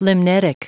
Prononciation du mot limnetic en anglais (fichier audio)
Prononciation du mot : limnetic